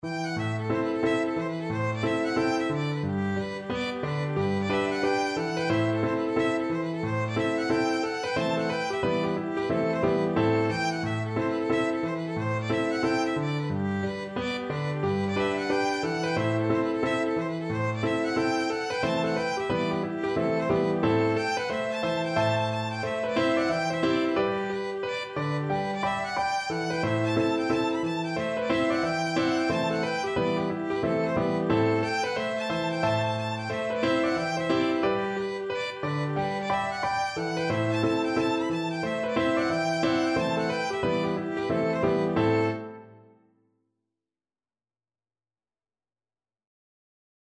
Violin
G major (Sounding Pitch) (View more G major Music for Violin )
Presto =c.180 (View more music marked Presto)
Traditional (View more Traditional Violin Music)